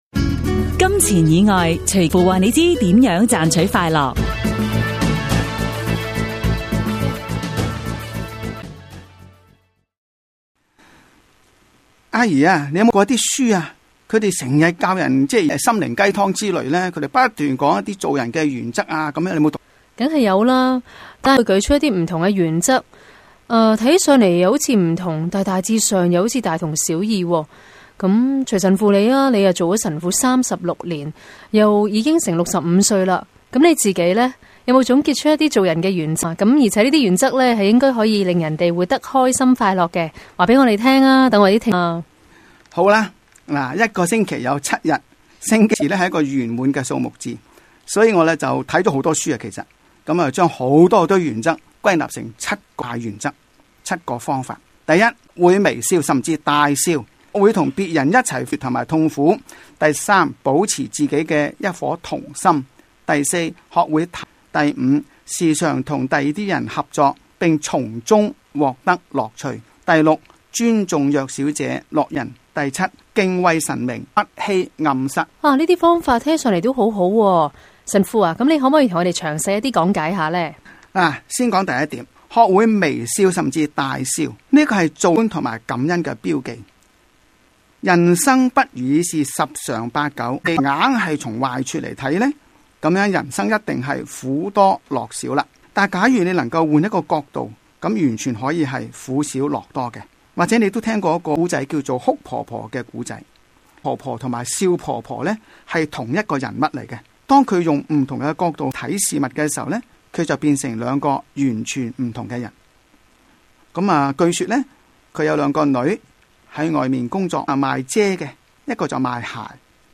自2007年底，我們在新城財經台推出「金錢以外」電台廣播節目，邀請不同講者及團體每晚以五分鐘和我們分享金錢以外能令心靈快樂、生命富足的生活智慧，讓聽眾感悟天主的美善和睿智。